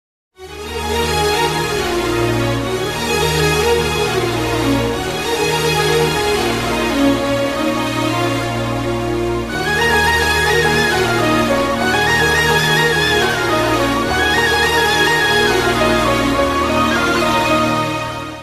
Category: Love Ringtones